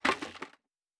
Plastic Foley Impact 3.wav